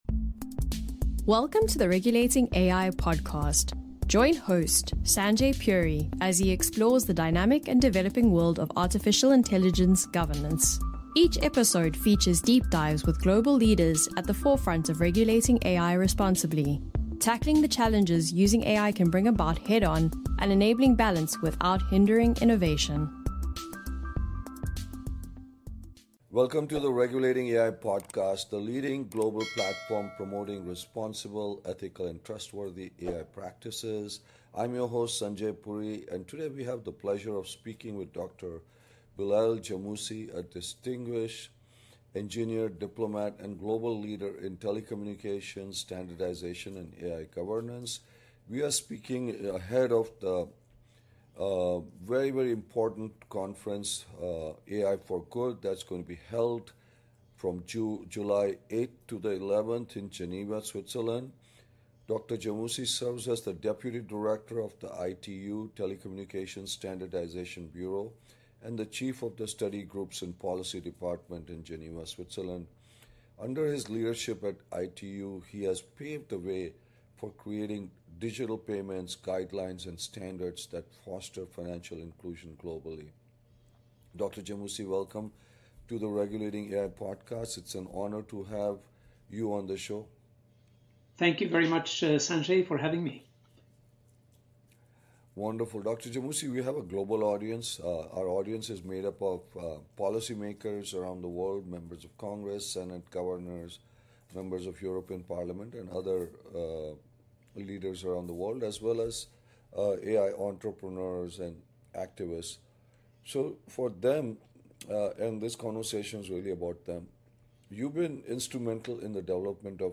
You can expect thought-provoking conversations with global leaders as they tackle the challenge of regulating AI without stifling innovation. With diverse perspectives from industry giants, government officials and civil liberty proponents, each episode explores key questions and actionable steps for creating a balanced AI-driven world.